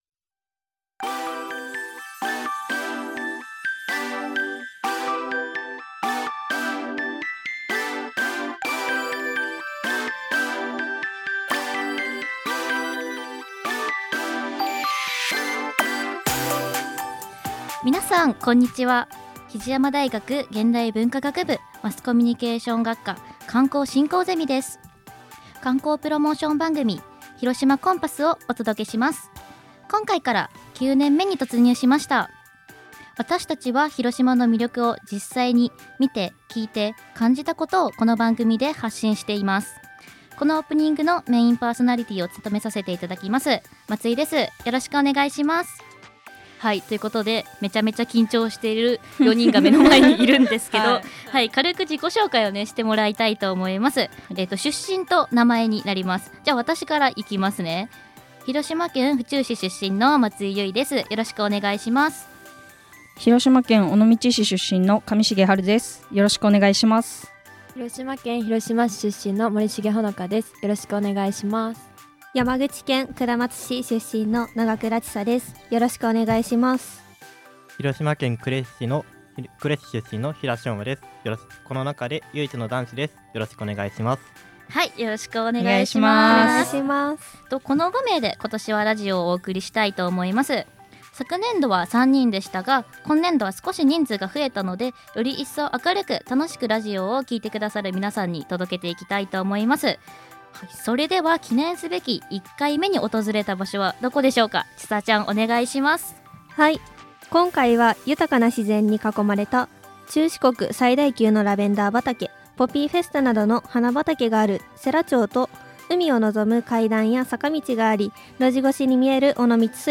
比治山大学presents 観光プロモーションラジオ番組「広島コンパス2025」
※著作権保護のため、楽曲は省略させて頂いています。